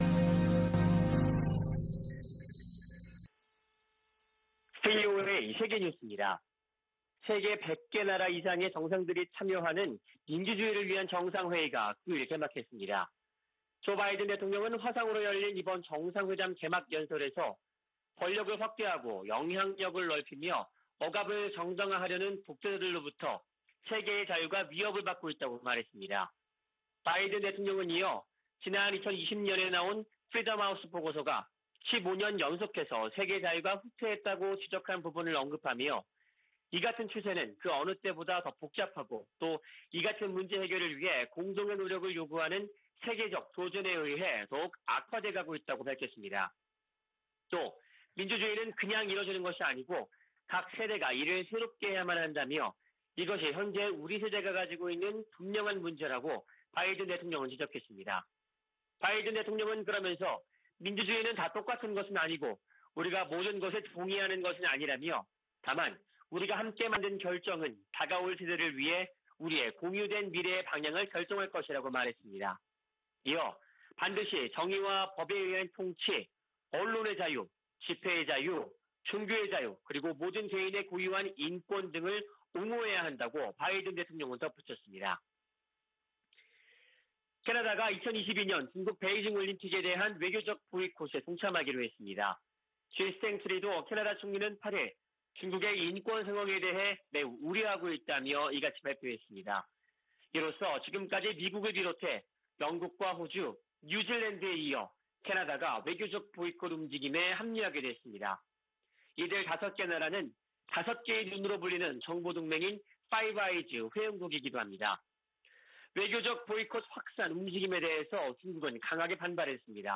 VOA 한국어 아침 뉴스 프로그램 '워싱턴 뉴스 광장' 2021년 12월 10일 방송입니다. 미국 공화당 하원의원 35명이 한국전쟁 종전선언을 반대하는 서한을 백악관에 보냈습니다. 한국 정부는 북한을 향해 종전선언 호응을 촉구하며 돌파구를 찾고 있지만 별다른 반응을 이끌어내지 못하고 있습니다. 미 상·하원 군사위가 합의한 2022회계연도 국방수권법안(NDAA) 최종안에는 '웜비어 법안' 등 한반도 안건이 대부분 제외됐습니다.